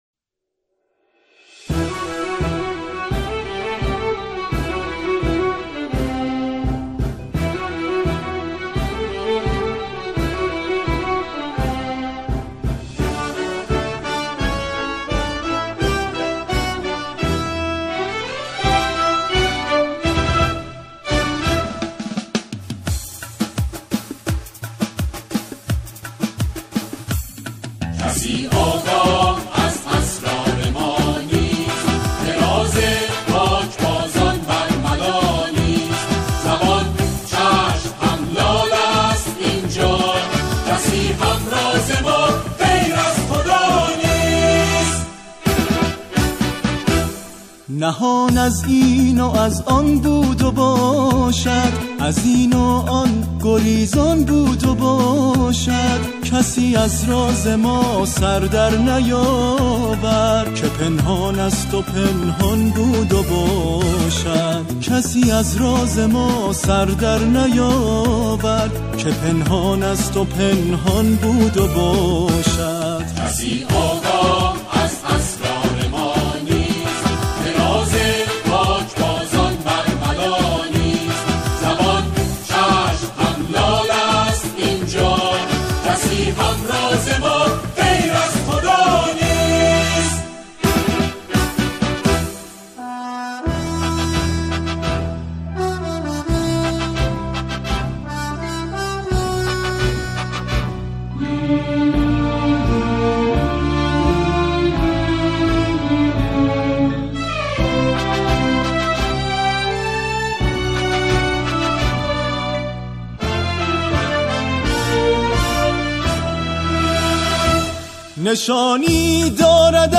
این قطعه، نمونه‌ای از جمعخوانی با دو خواننده اصلی است.